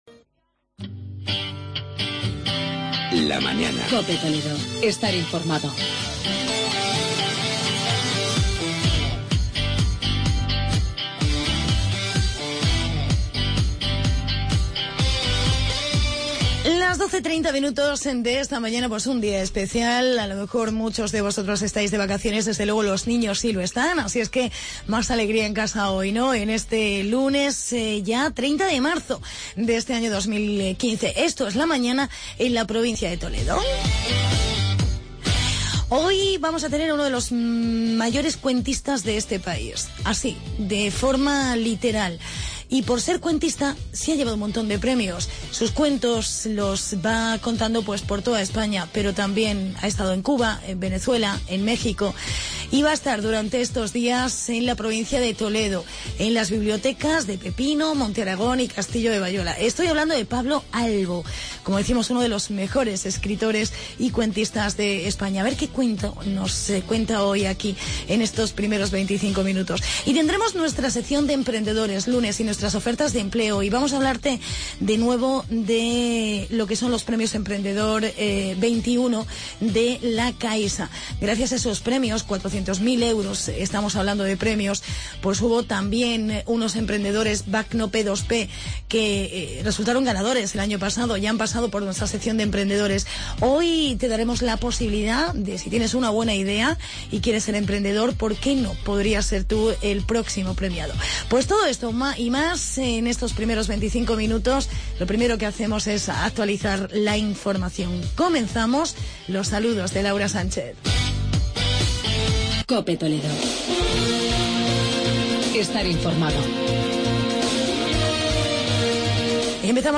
COPE TALAVERA